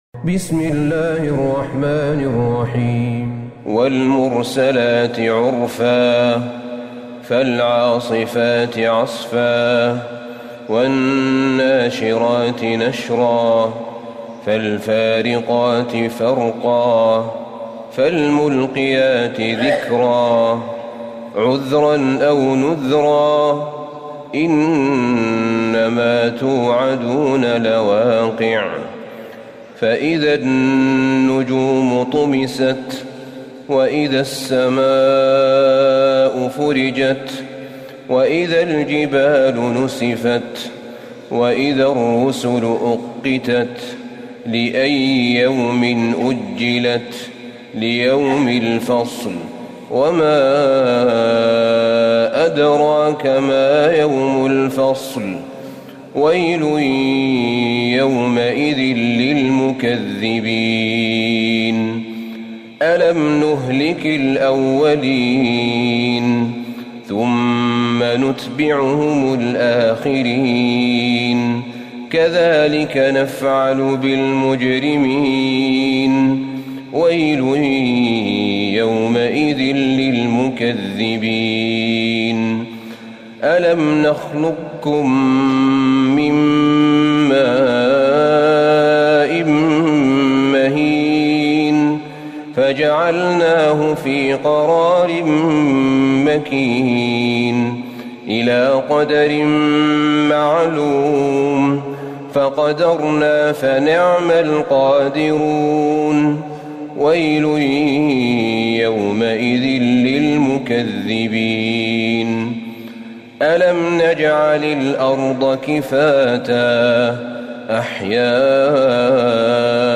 سورة المرسلات Surat Al-Mursalat > مصحف الشيخ أحمد بن طالب بن حميد من الحرم النبوي > المصحف - تلاوات الحرمين